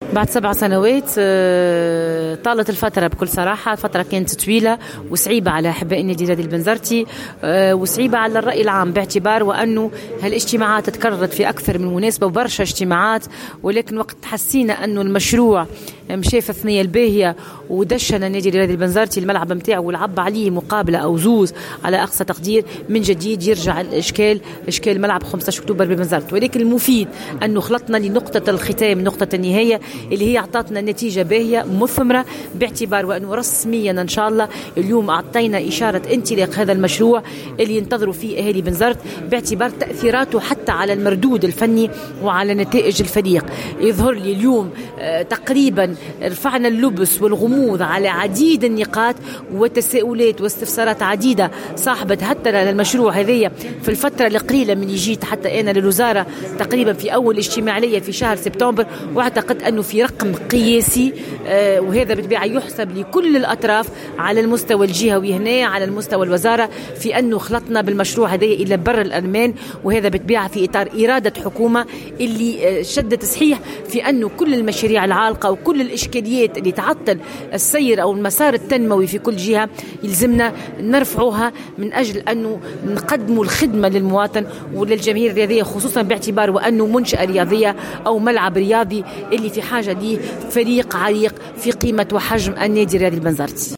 رسمي:الإعلان عن انطلاق أشغال مشروع تعشيب الأرضية الرئيسية لملعب 15اكتوبر (تصريح)